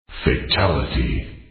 51cry.mp3